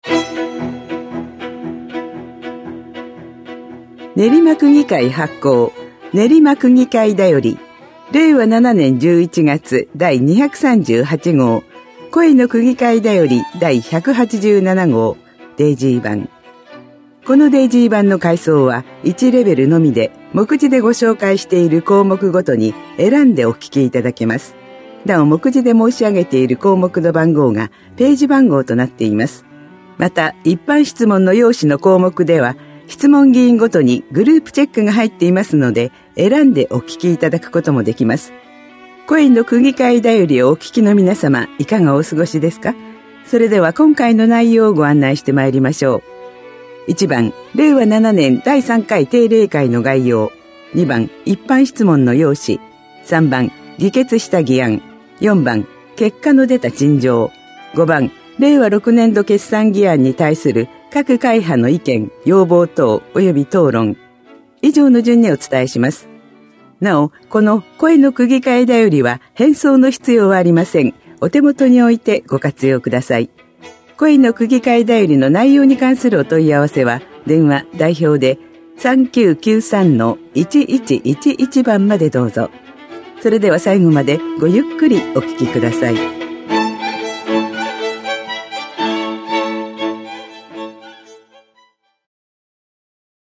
声の区議会だより（音声データ）
練馬区議会では、目の不自由な方のために、デイジーによる「声の区議会だより」を発行しています。